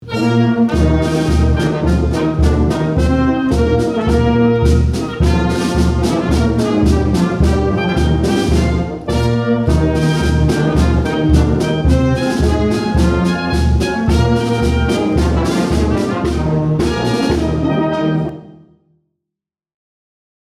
These are excerpts from a live performance at Lakestone Terrace at 4:00 pm, Tuesday, October 6, 2022.